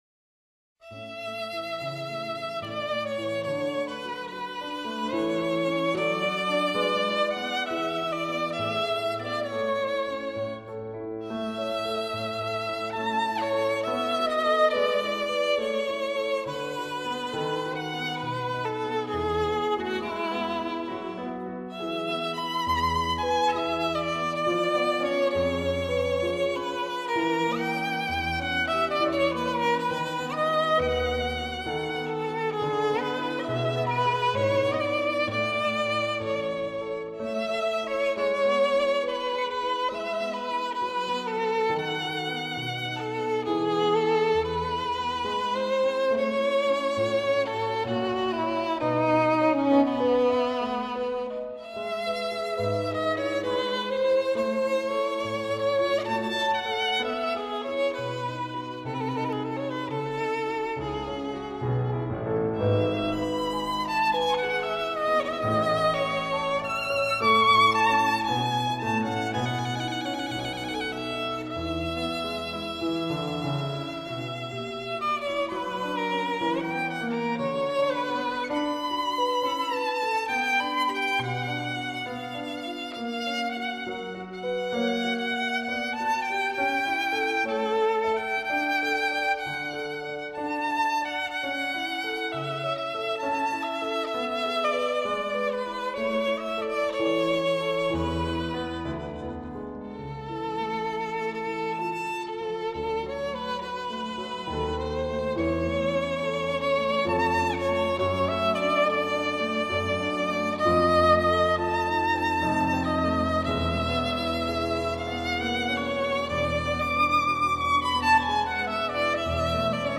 无论从演技到音质都无可挑剔，曲调优美，演奏细腻，丝丝入扣，是发烧友不可多得的一张试音天碟。